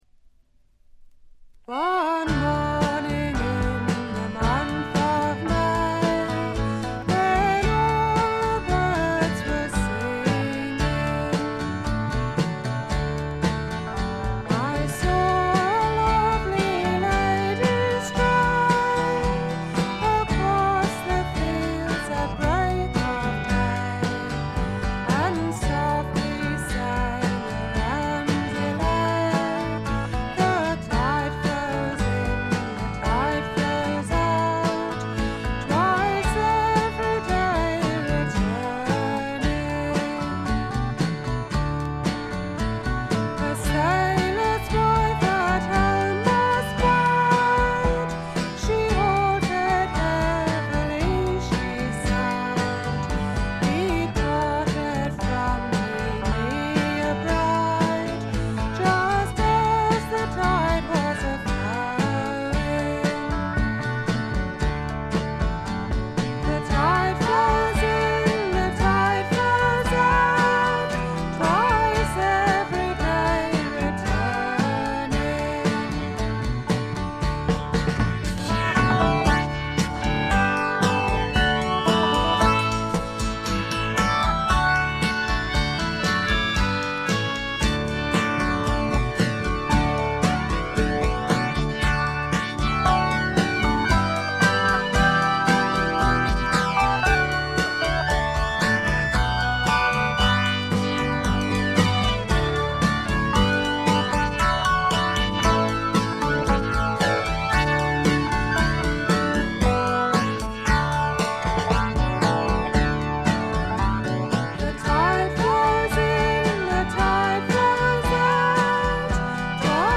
エレクトリック・トラッド最高峰の一枚。
試聴曲は現品からの取り込み音源です。